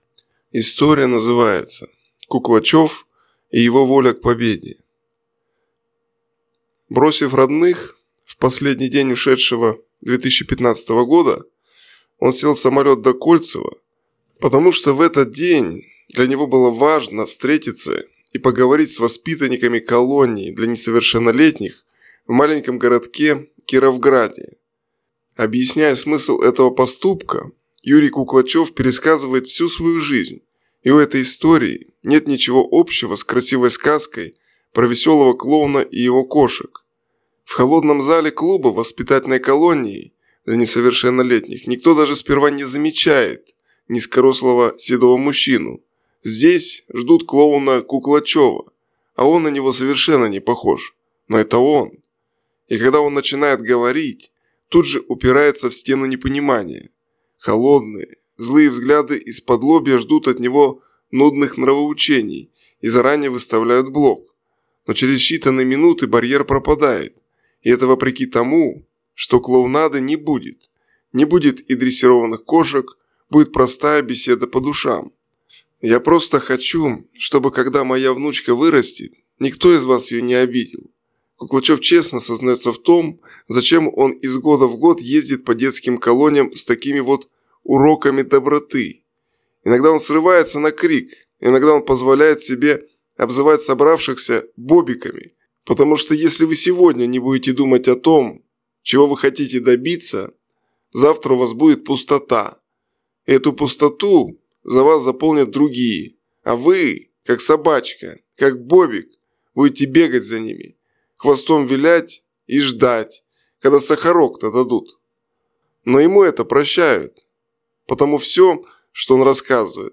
В холодном зале клуба воспитательной колонии для несовершеннолетних никто сперва даже не замечает низкорослого седого мужчину.
Будет простая беседа по душам.